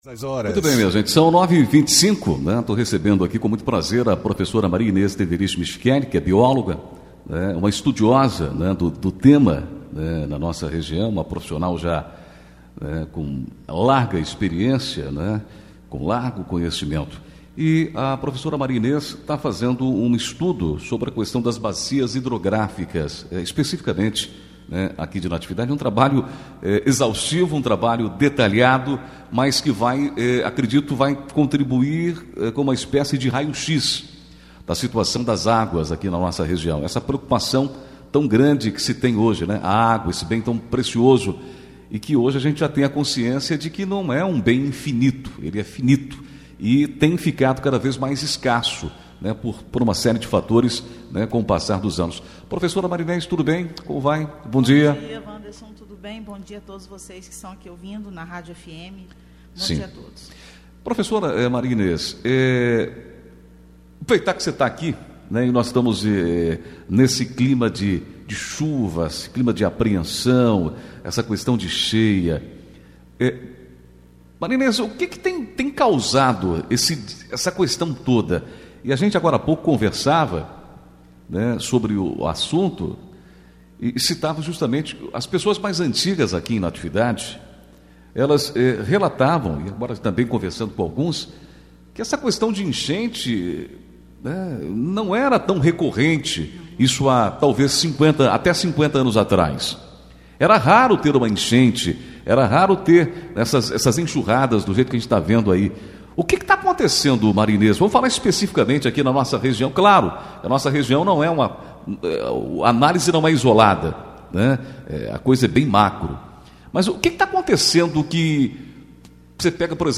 15 fevereiro, 2022 ENTREVISTAS, NATIVIDADE AGORA